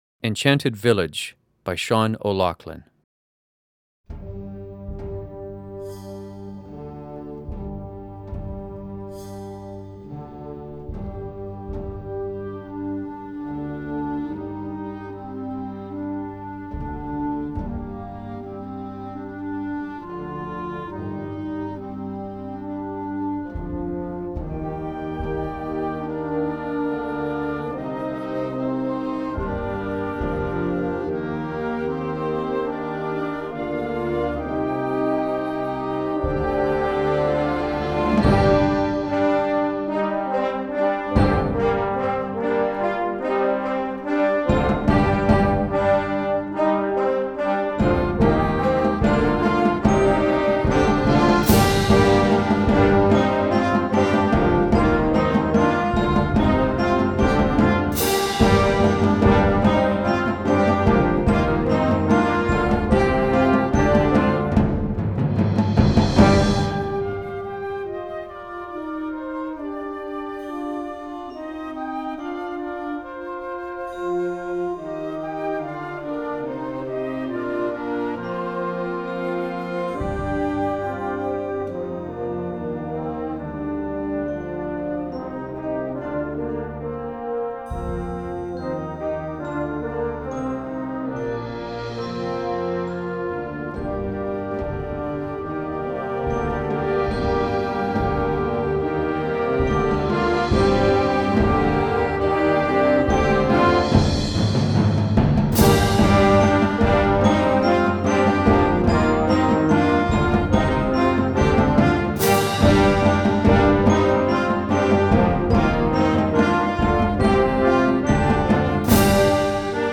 is a rather stern piece (in natural minor)